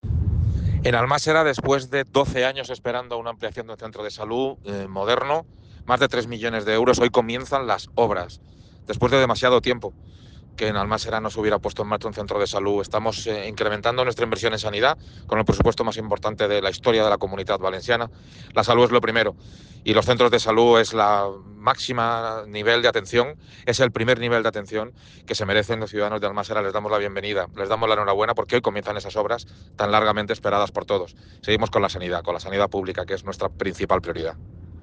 El máximo representante del Ejecutivo valenciano ha realizado una visita institucional al Ayuntamiento de Almàssera junto con el conseller de Sanidad, Marciano Gómez, donde han tratado junto al alcalde de la localidad, Emilio Belencoso, el proyecto de ampliación que la Generalitat va a acometer en el municipio.
TOTAL-PRESIDENT-CENTRO-SALUD-ALMASSERA.mp3